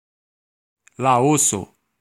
Ääntäminen
Ääntäminen US Tuntematon aksentti: IPA : /ˈlaʊs/ IPA : /ˈlɑːoʊs/ Haettu sana löytyi näillä lähdekielillä: englanti Käännös Ääninäyte Erisnimet 1.